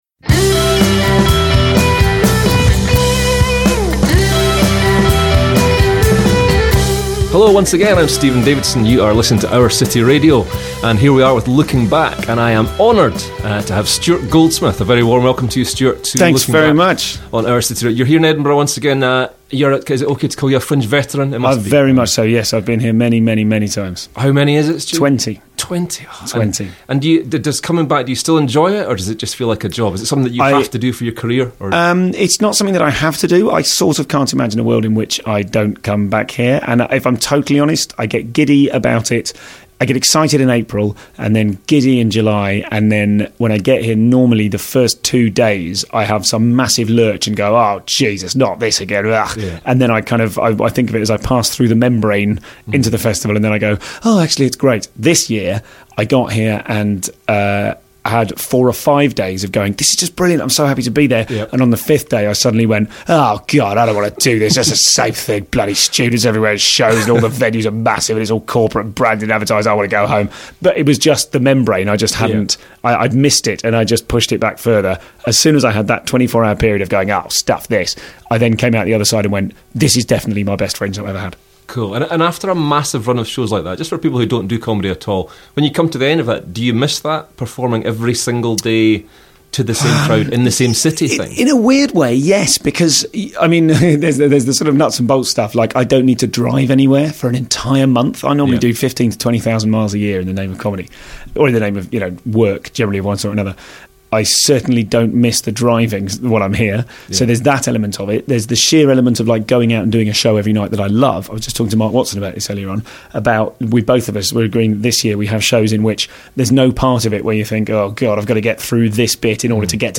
Recorded during the Edinburgh Fringe Festival 2014.